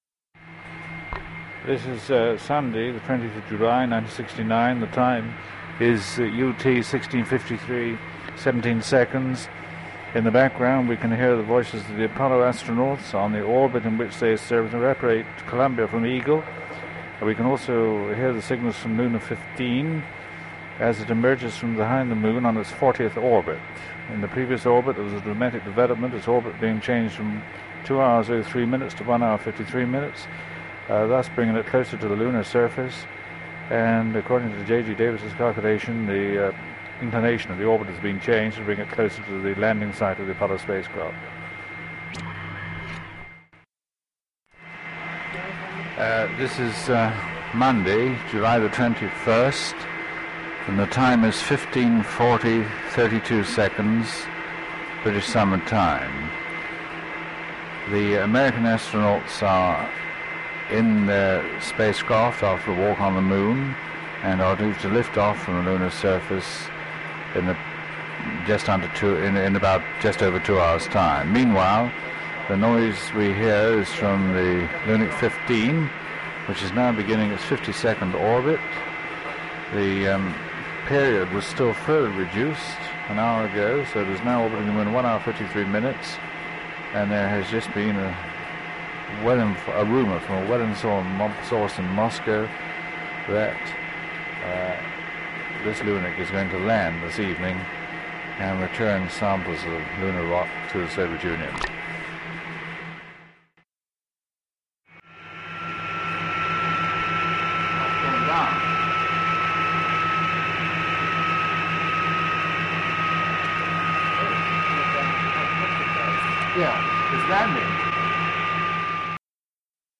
Made over three days in mid-July, Sir Bernard Lovell can be heard narrating events. Transmissions from the Apollo 11 astronauts can also be heard in the background. Sir Bernard notes a change in the orbit of Luna 15 to take it closer to the US landing site and later reports a rumour from a ‘well-informed source in Moscow’ that the craft is about to land.
People in the Control Room can then be heard exclaiming ‘it’s landing’ and ‘it’s going down much too fast’ as they track Luna 15’s final moments before it crashes.
“In the background we can hear the voices of the Apollo astronauts…and we can also hear the signals from Luna 15 as it emerges from behind the Moon on its fortieth orbit.” (Sir Bernard Lovell)